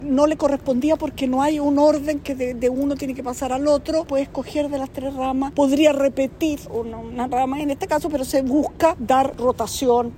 En ese sentido, ante los cuestionamientos por la “rotación”, la secretaria de Estado indicó que “no hay un orden”, apuntando a que “el Presidente puede escoger de las tres ramas de las Fuerzas Armadas”.